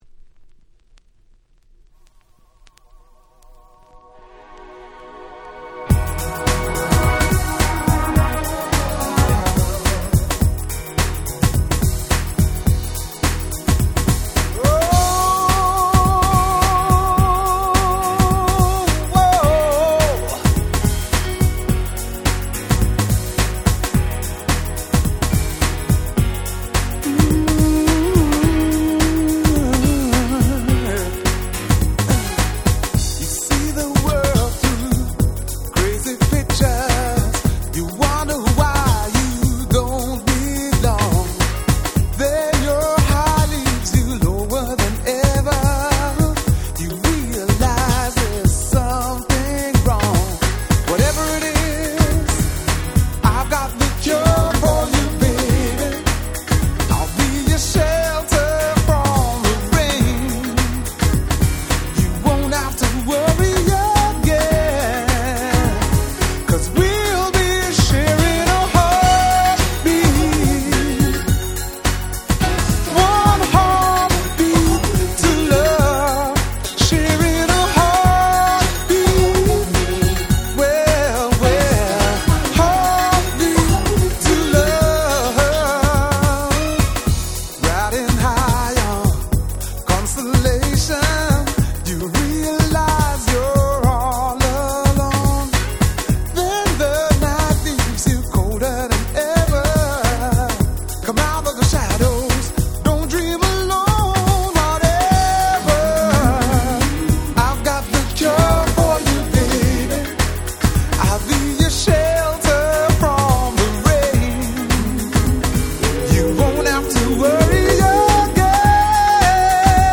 SweetSoulfulな男性Vocal物。
間奏のSaxパートもバッチリ！！